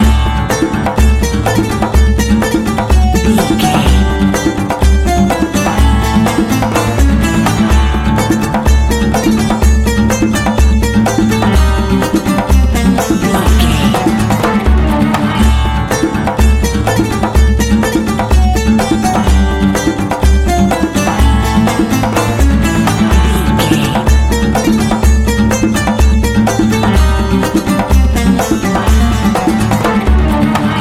Phrygian
Slow
bright
festive
dreamy
mystical
synthesiser
percussion
acoustic guitar
strings